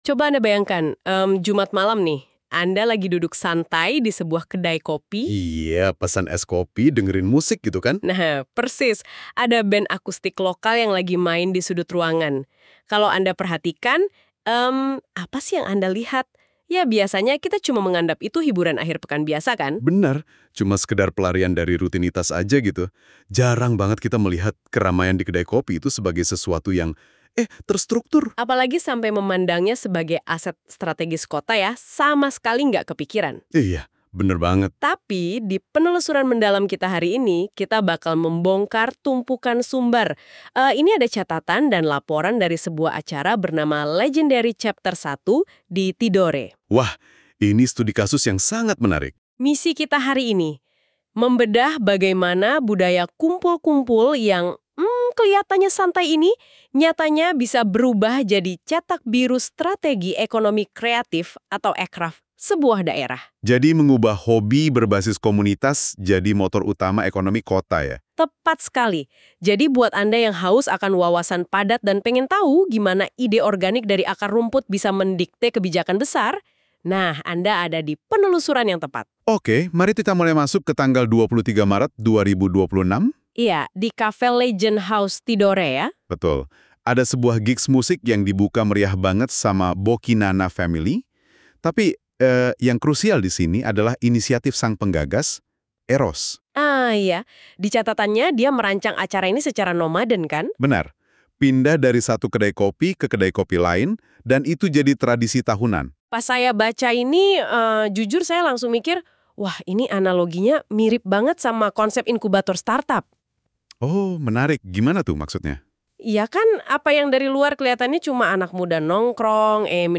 Audio podcast ini diproduksi menggunakan aplikasi NotebookLM by Google